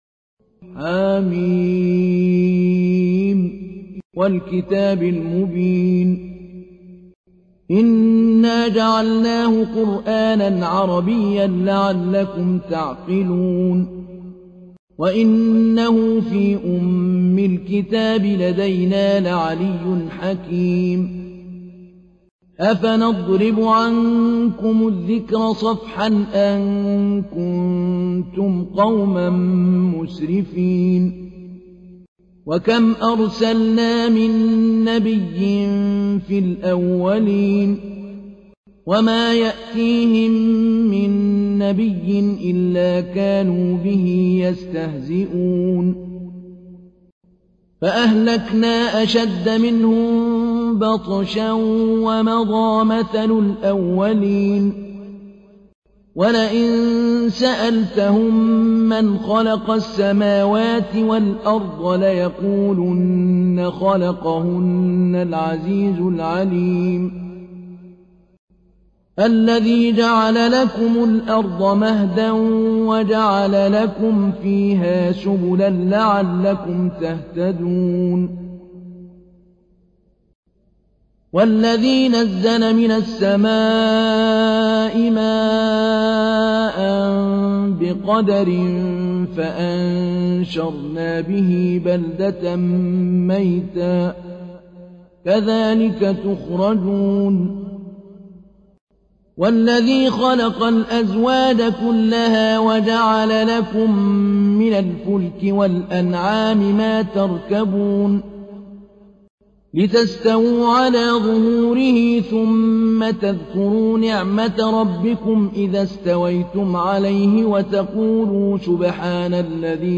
تحميل : 43. سورة الزخرف / القارئ محمود علي البنا / القرآن الكريم / موقع يا حسين